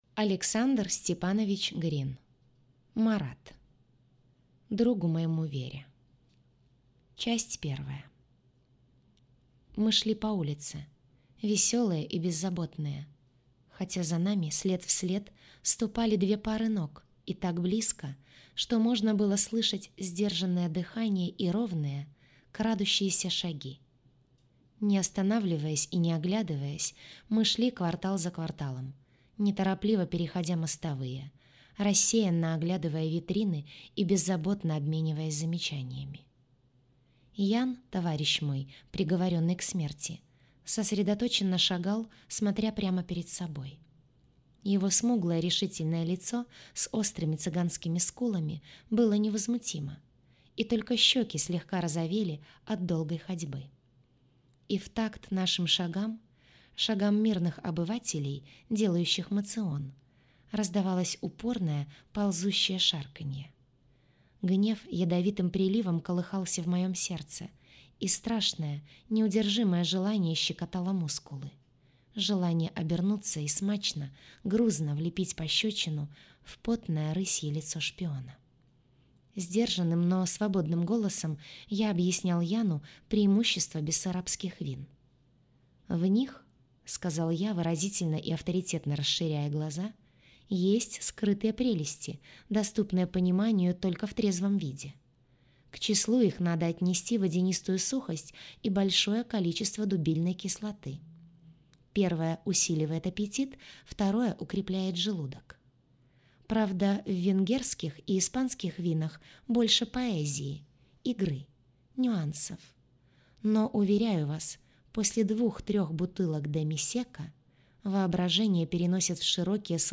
Аудиокнига Марат | Библиотека аудиокниг